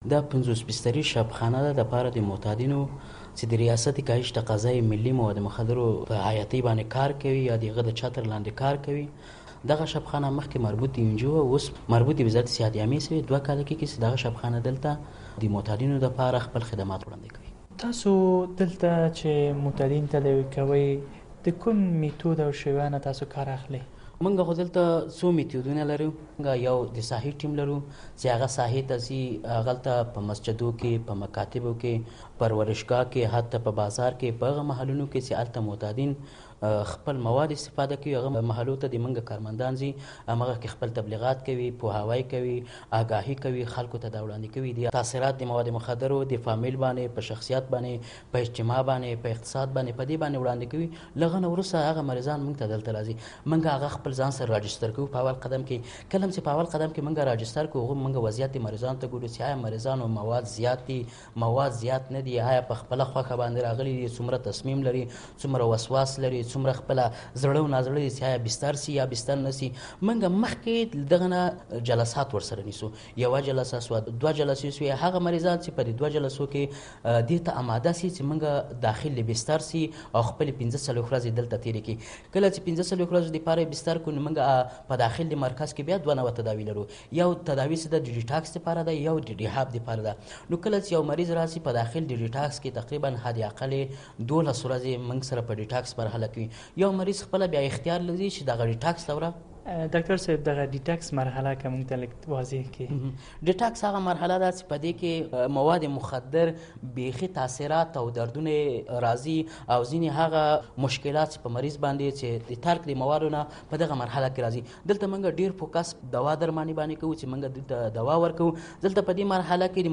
د معتادینو د درملنې په اړه مرکه